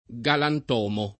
galantomo [ g alant 0 mo ] → galantuomo